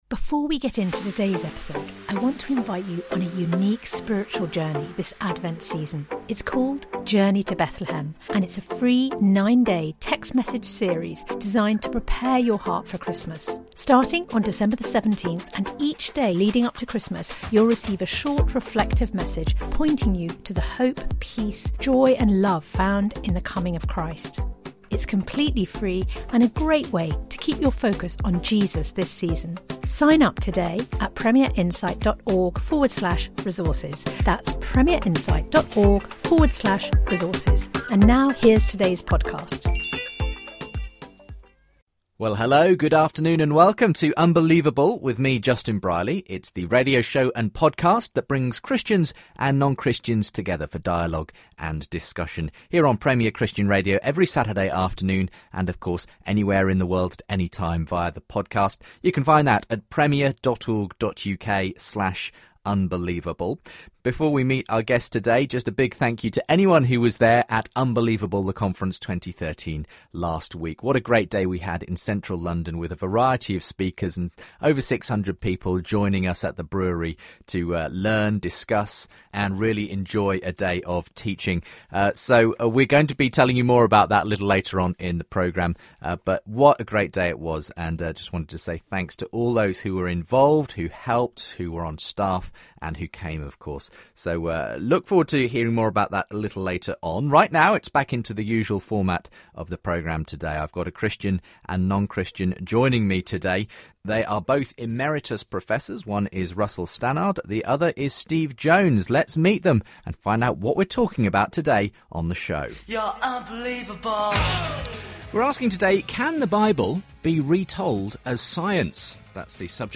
They debate whether the Bible can be seen as scientific, what kinds of questions religion and science answer and more.